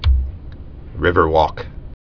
(rĭvər-wôk)